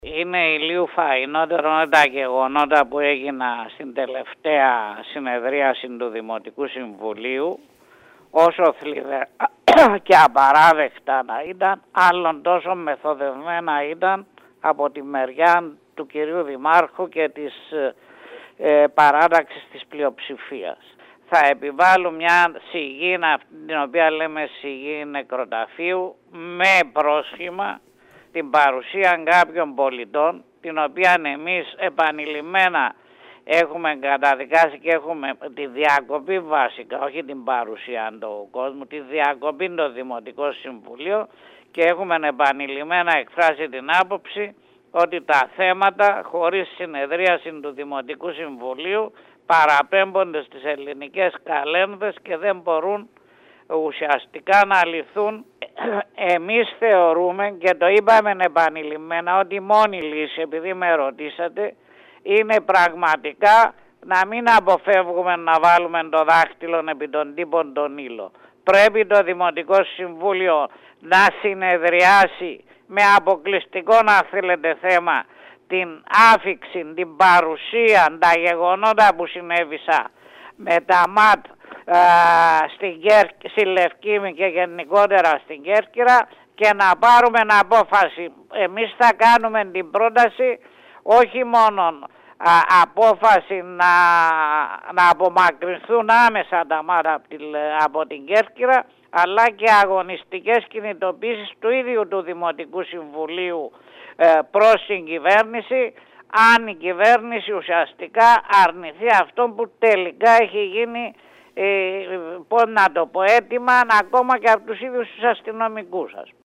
Μιλώντας στο σταθμό μας, ο επικεφαλής της ΛΑΣΥ Μπάμπης Χαραλάμπους άσκησε κριτική στη δημοτική αρχή για τον τρόπο που προσπάθησε να οργανώσει τη συνεδρίαση του σώματος,  καταδίκασε ωστόσο την όποια προσπάθεια διακοπής της λειτουργίας του Δημοτικού Συμβουλίου και δήλωσε ότι μόνη λύση είναι η ψήφιση από το Δημοτικό Συμβούλιο της απομάκρυνσης των αστυνομικών δυνάμεων από τη Λευκίμμη.